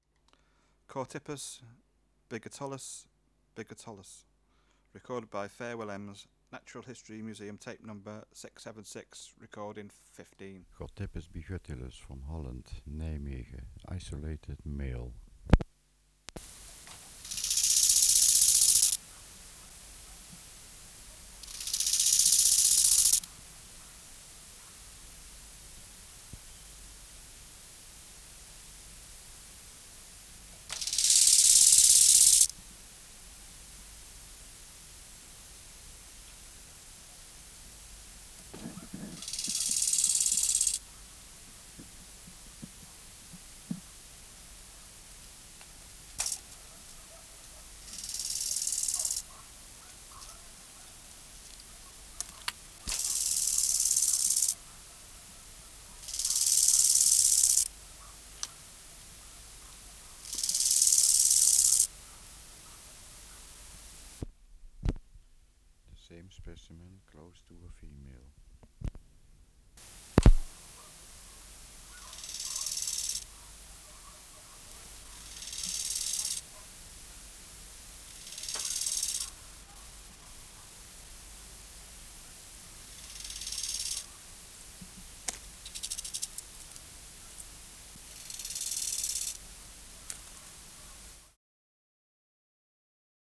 568:15 Chorthippus biguttulus biguttulus (676r15) | BioAcoustica
Extraneous Noise: Dogs barking Substrate/Cage: In cage
Isolated male
Microphone & Power Supply: AKG D202 E (LF circuit off) Distance from Subject (cm): 15
Recorder: Uher 4200